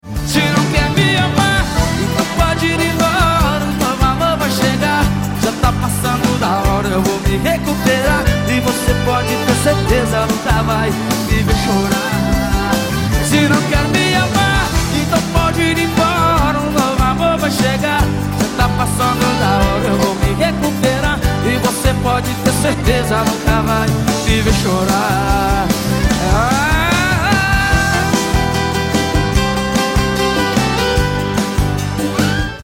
поп
гитара
мужской вокал
Бразильские
струнные